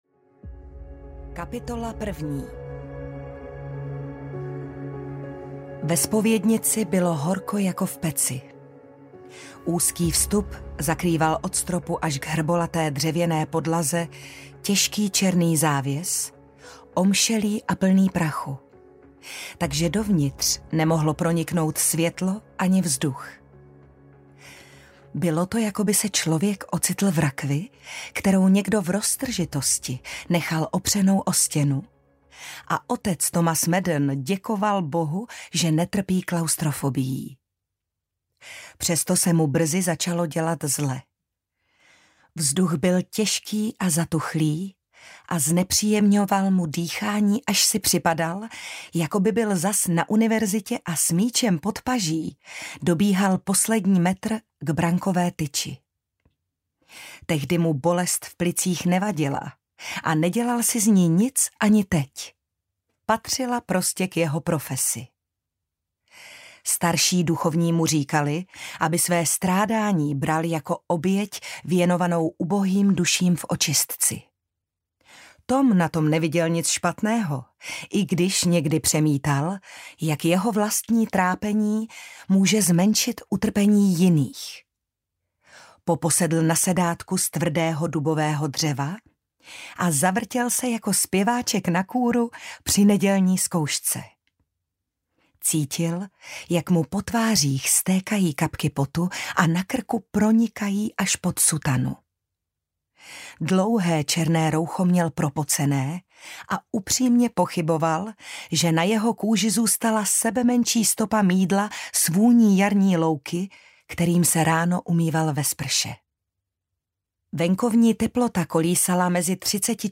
Nebezpečná hra audiokniha
Ukázka z knihy